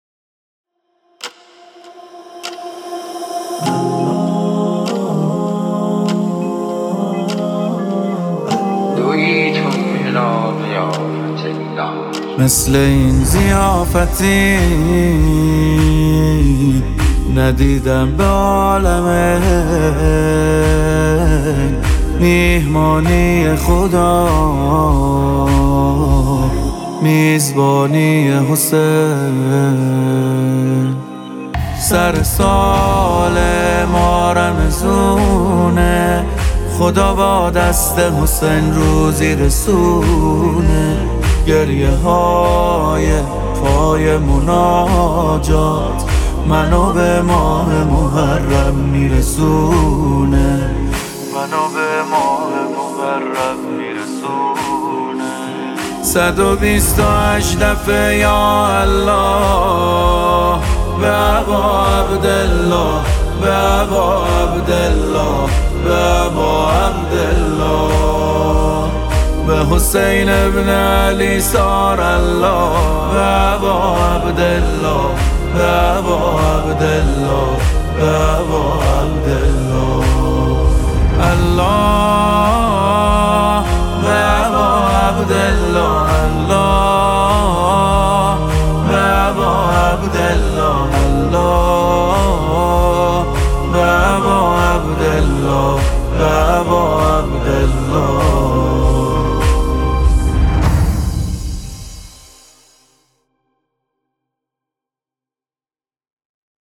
نماهنگ استودیویی